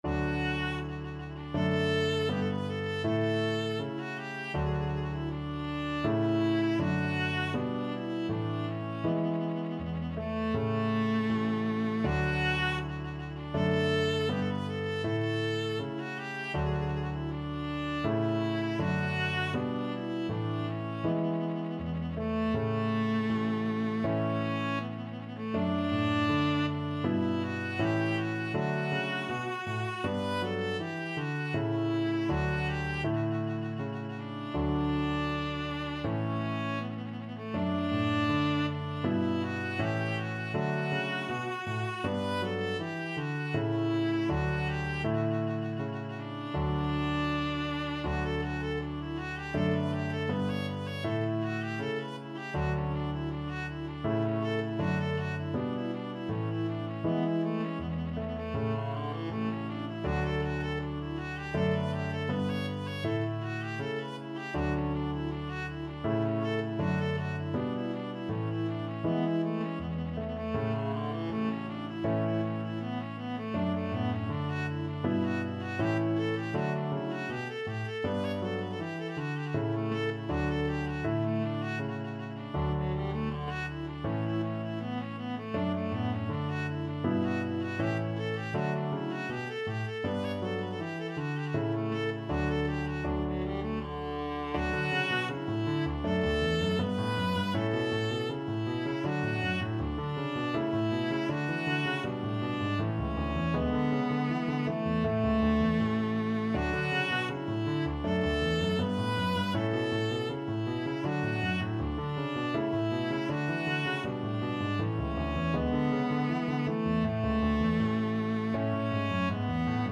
Allegretto =80
4/4 (View more 4/4 Music)